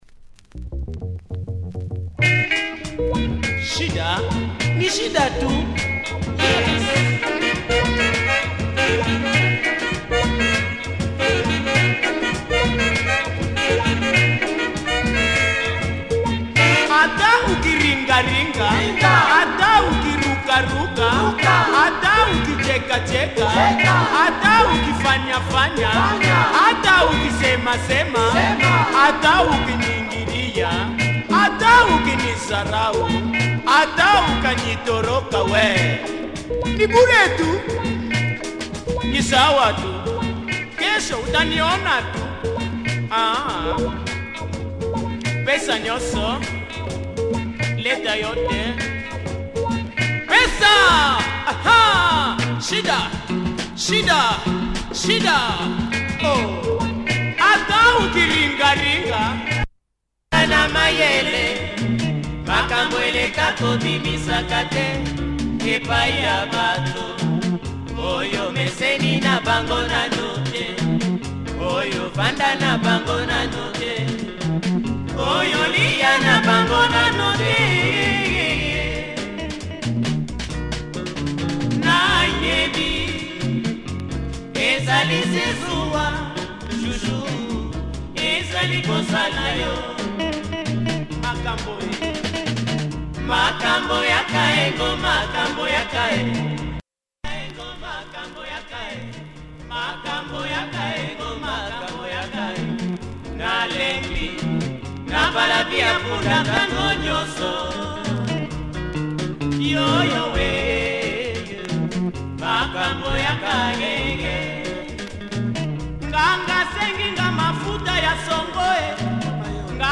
some wah-wah guitar in there too?